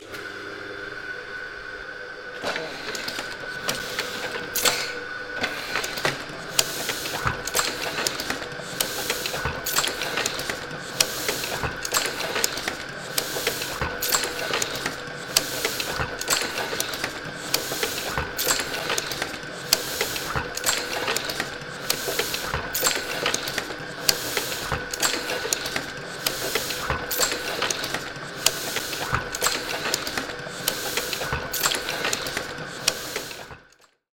The source recording was made in January 2015 at the John Jarrold Printing Museum in Norwich. It’s an old printing machine that was used on this site a number of years before. The machine is called The “Heidelberg” Automatic Platen.